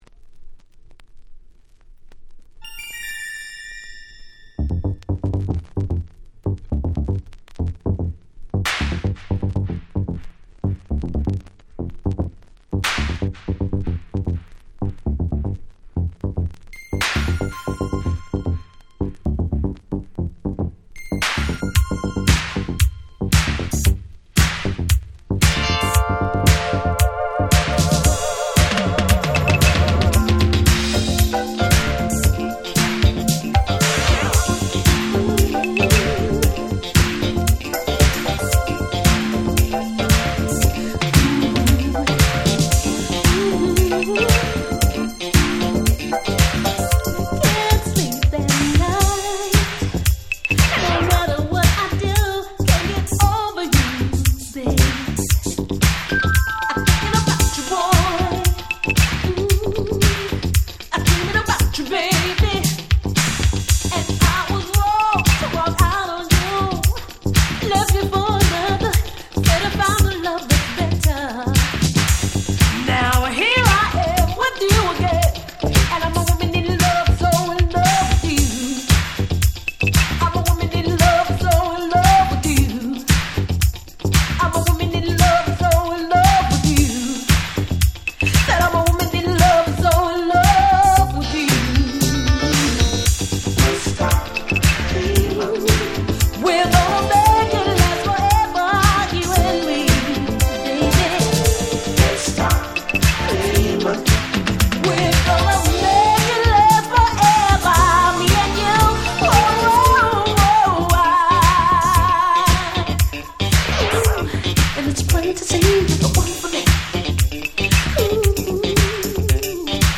84' Very Nice Disco / Boogie / Funk !!
爽やかでFunkyなDisco Track ！！